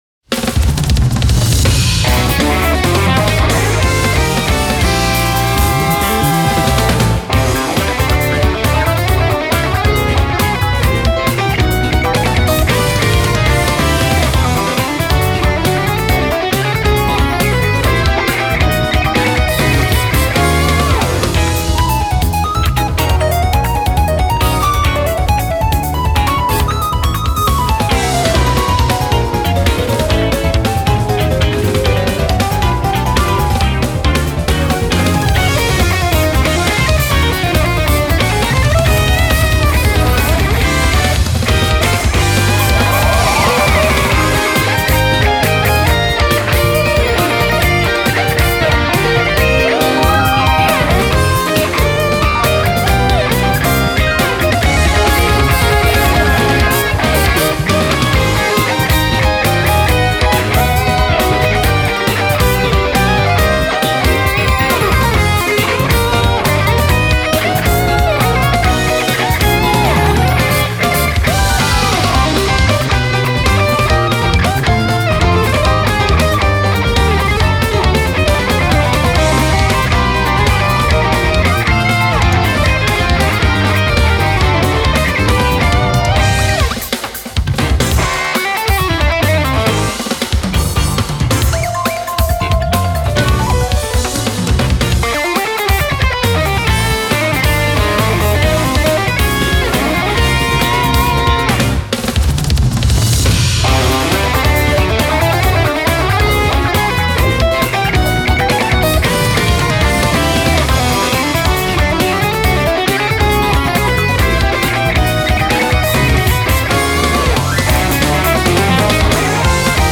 BPM137
Genre: Instrumental Rock